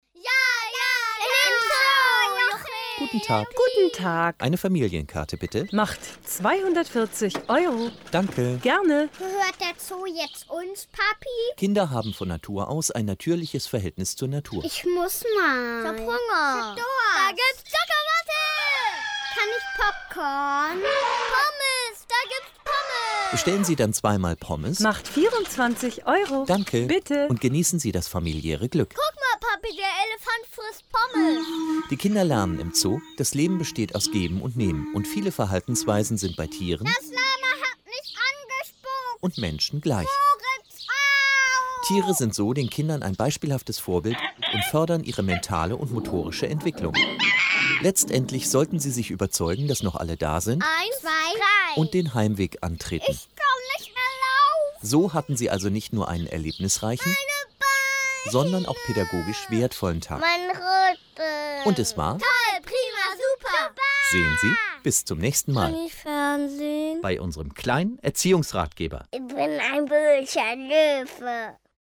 Audio/Hörbuch
Der kleine Erziehungsratgeber - Die aus HR, WDR, MDR, SWR, SR und NDR bekannte Radiocomedy gibt es jetzt komplett auf einem besonders schönen USB-Stick aus Metall mit einer Schutzlasche aus Leder und Magnetverschluss, verpackt in einer Metalldose.